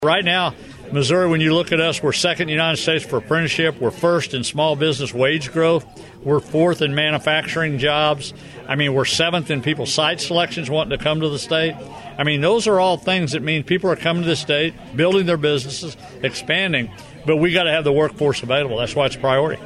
After signing House Bill 574 at Trenton on June 10th, Governor Mike Parson spoke about the role of community colleges, like North Central Missouri College, in his workforce development plan.